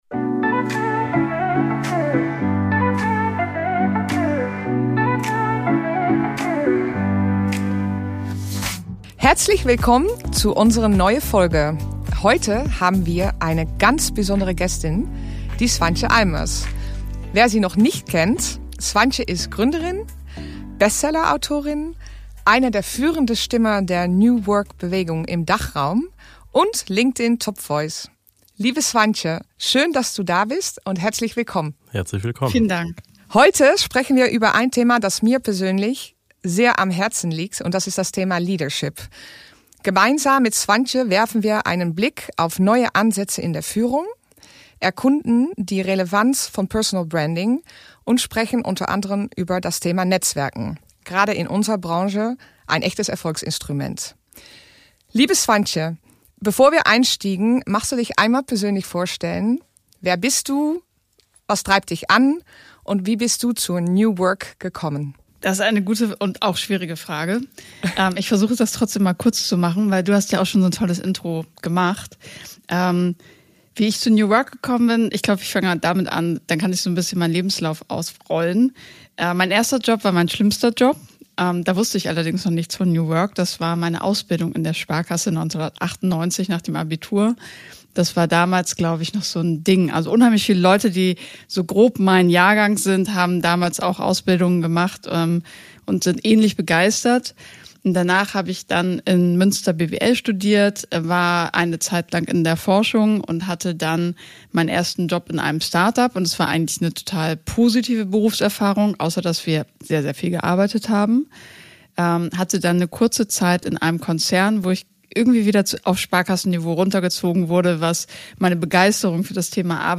Beschreibung vor 3 Monaten Leadership: Neue Ansätze in der Führung In Episode 3 der Page Executive Leadership Podcast-Serie beleuchten wir die sich wandelnde Führungslandschaft und diskutieren, wie Authentizität, Flexibilität und Personal Branding die Definition von Leadership in der heutigen Welt neu gestalten.